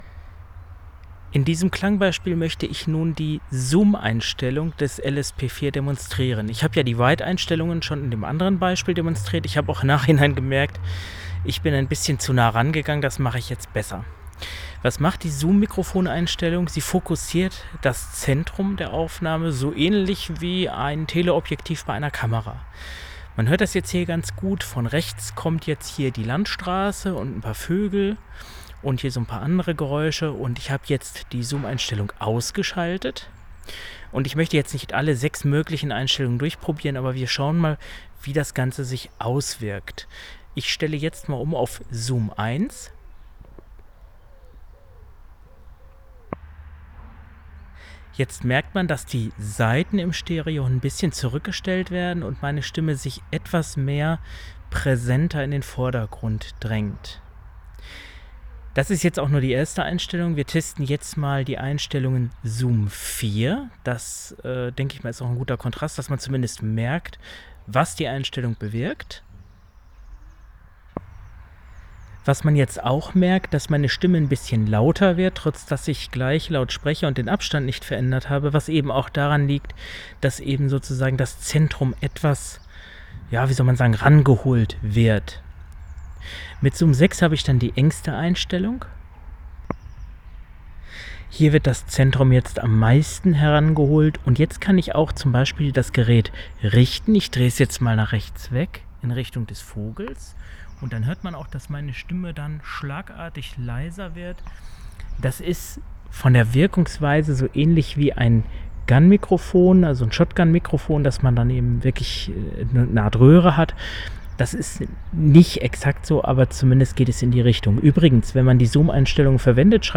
olympus_ls_p4_vergleich_der_automatischen_aussteuerungen.mp3